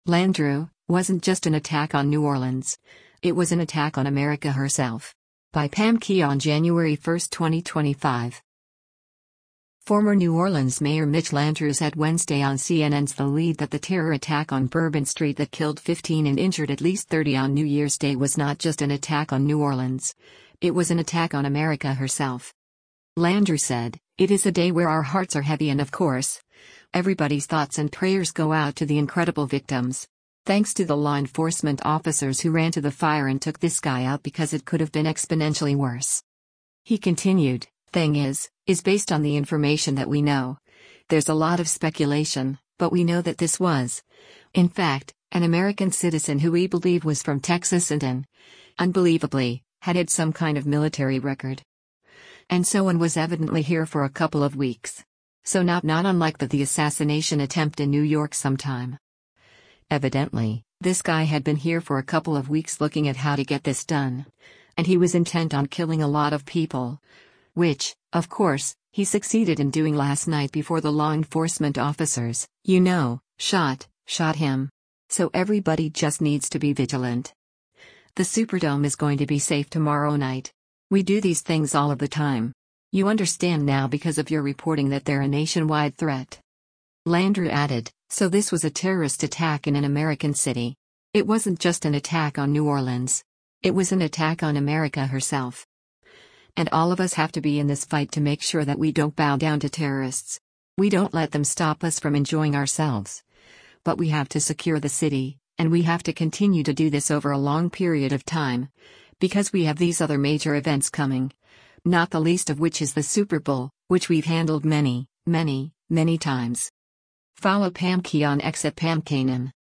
Former New Orleans Mayor Mitch Landrieu said Wednesday on CNN’s “The Lead” that the terror attack on Bourbon Street that killed 14 and injured 35 on New Year’s Day was not “just an attack on New Orleans, it was an attack on America herself.”